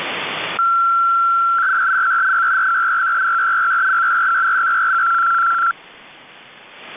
Запись сигнала на опознание